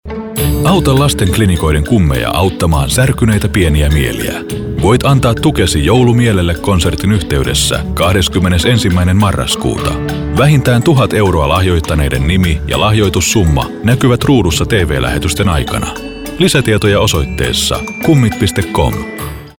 Kein Dialekt
Sprechprobe: Werbung (Muttersprache):
Voice over artist finnish for 20+ years, thousands of projects.